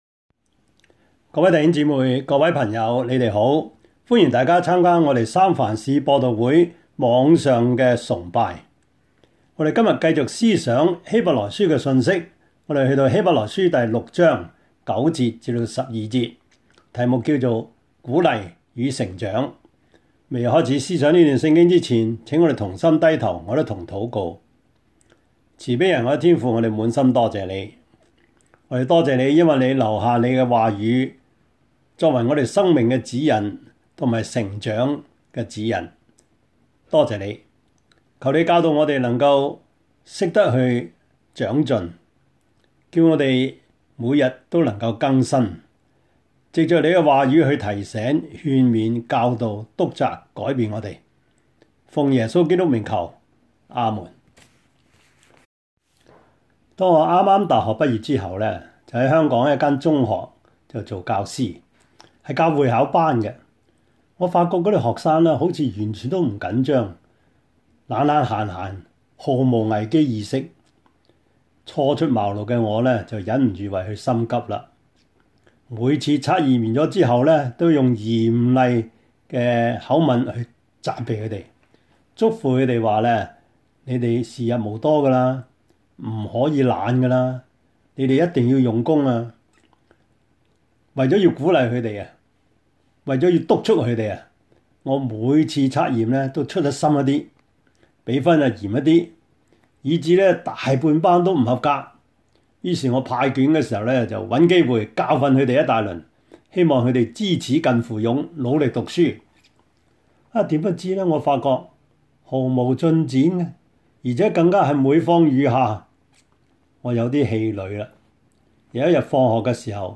Service Type: 主日崇拜
Topics: 主日證道 « 如何解說聖經的預言?